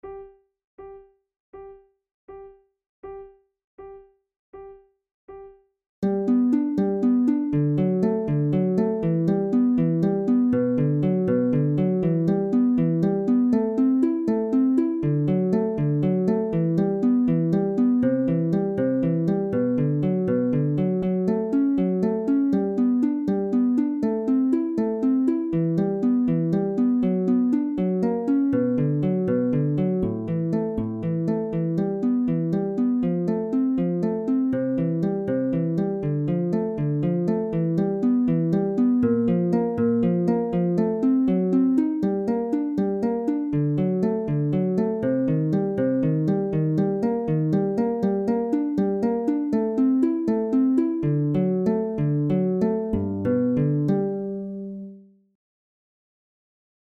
for two flutes and harp
Categories: Contemporary Difficulty: easy